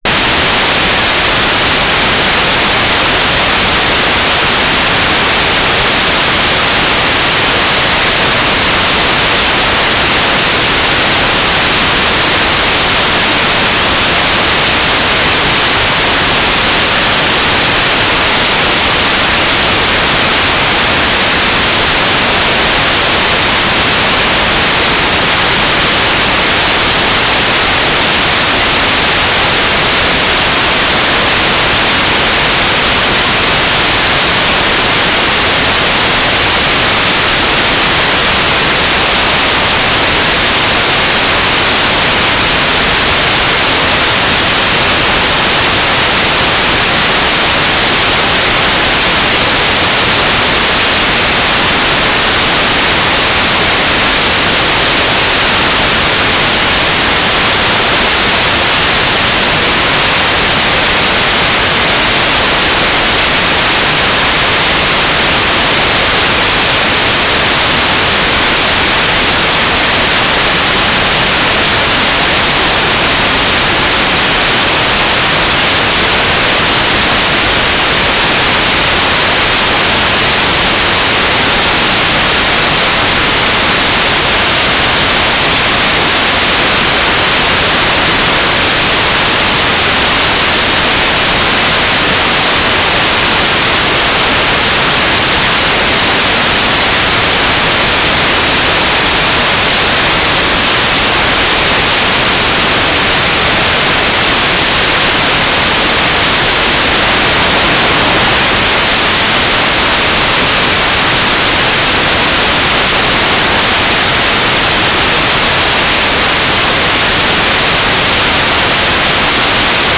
"transmitter_description": "Mode U - GFSK9k6 AX.25/G3RUH - SpaceQuest TRX-U",